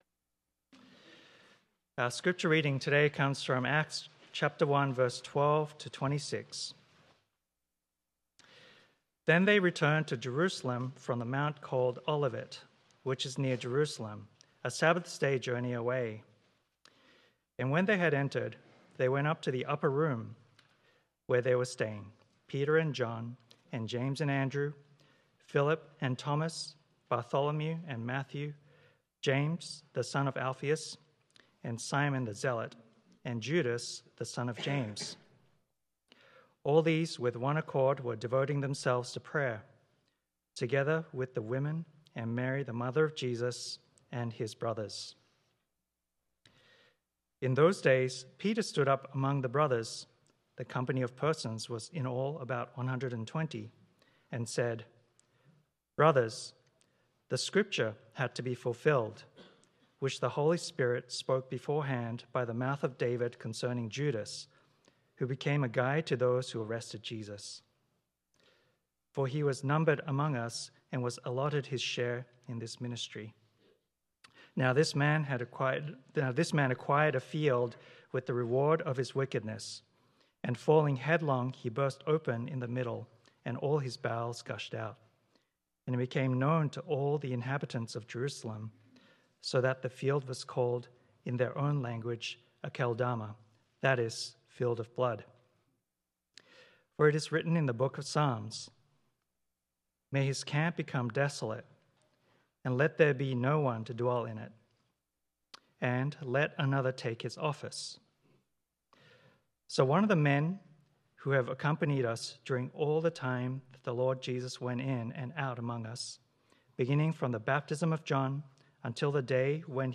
1.25.26 Sermon.m4a